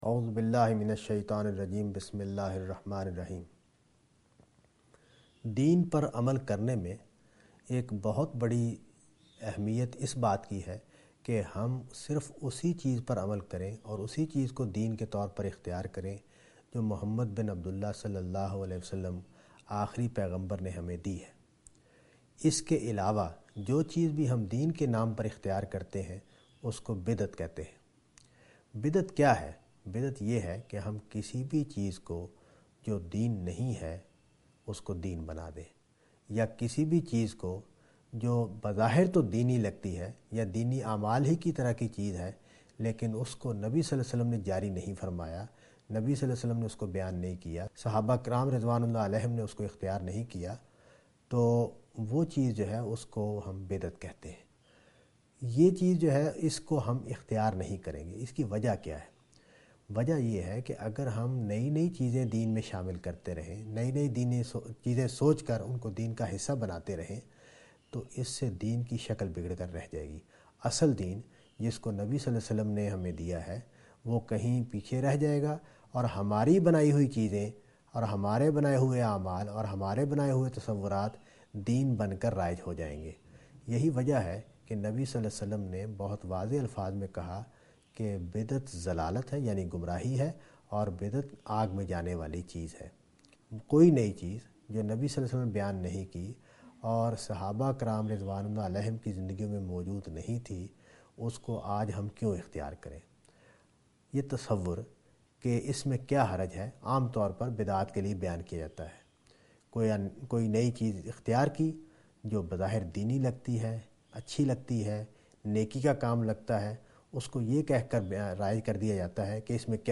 Category: Associate Speakers